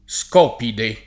[ S k 0 pide ]